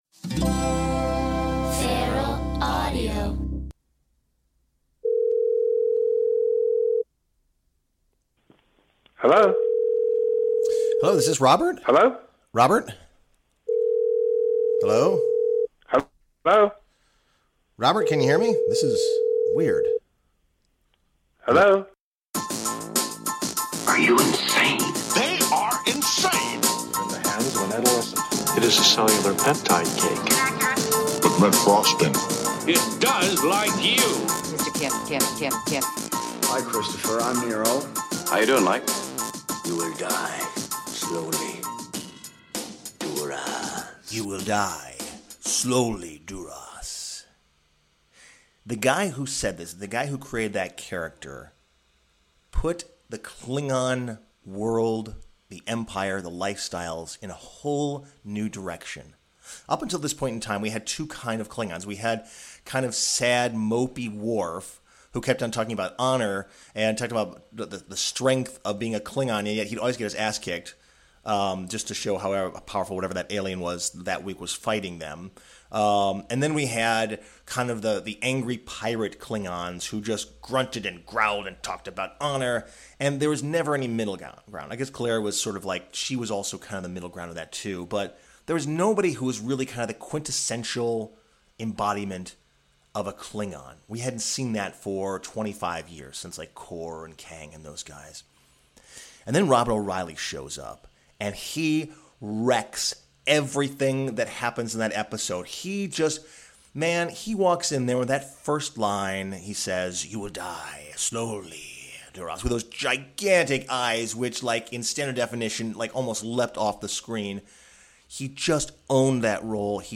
Today IS a Good Day to Interview Chancellor Gowron (Robert O' Reilly)!